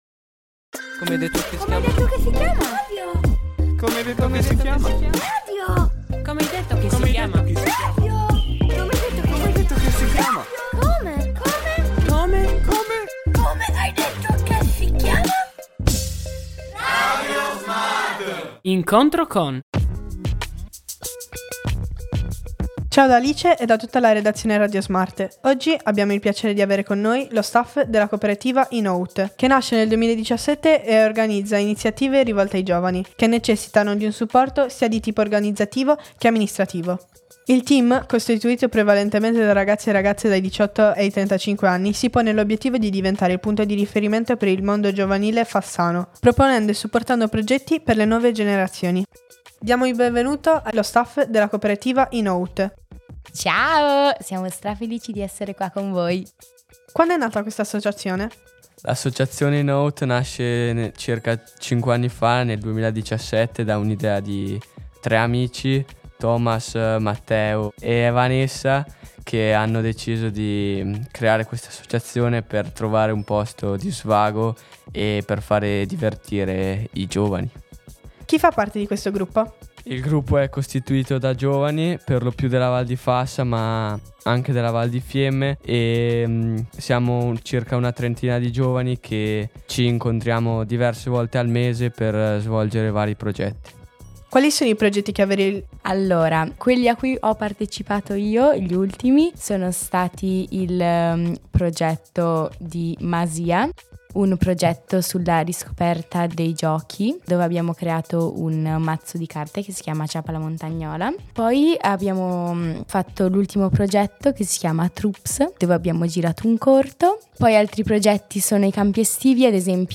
Alcune foto scattate durante la registrazione dell’intervista